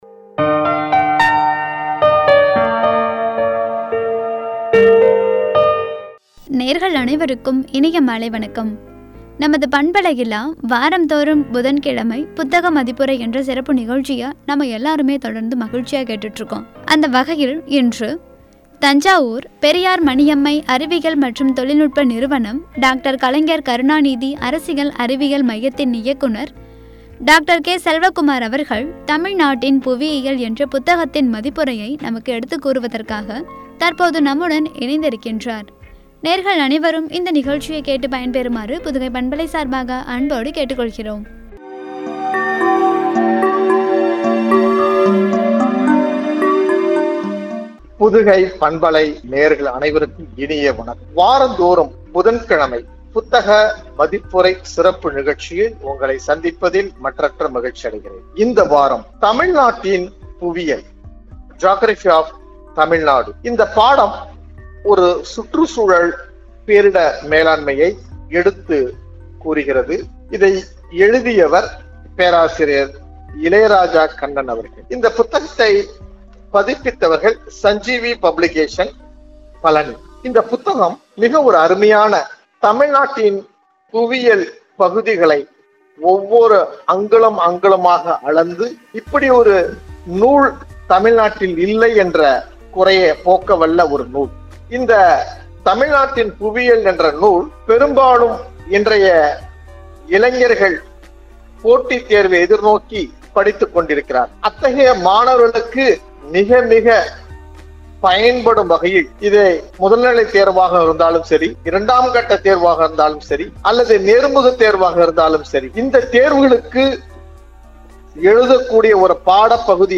புத்தக மதிப்புரை